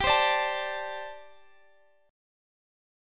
Gsus2sus4 Chord
Listen to Gsus2sus4 strummed